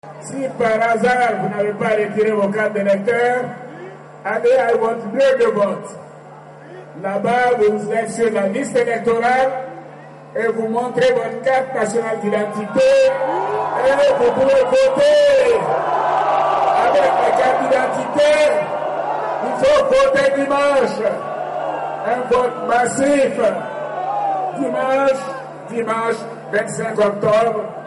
Le président sortant ivoirien a, dans son dernier discours de campagne tenu vendredi à Abidjan, appelé à un vote massif en sa faveur.
Alassane Ouattara lors du dernier meeting de sa campagne électorale pour la présidentielle du 25 octobre 2015, à Abidjan,